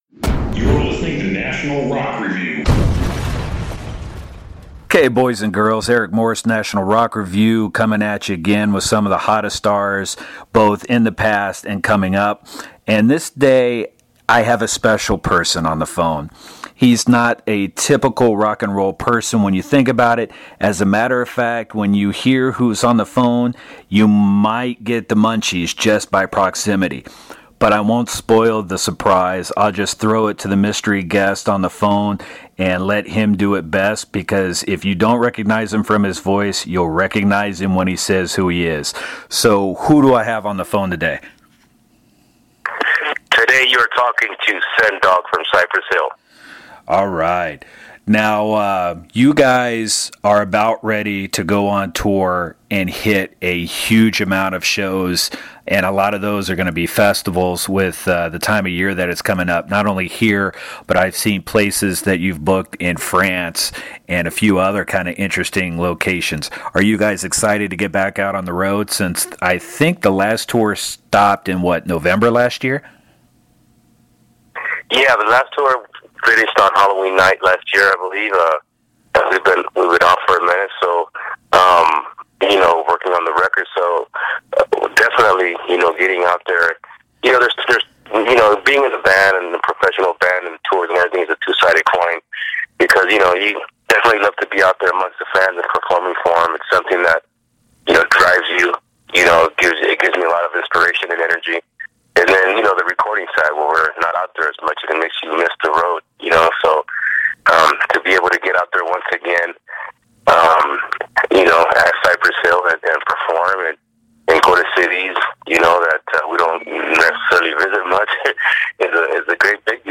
Interview with Sen Dog of Cypress Hill: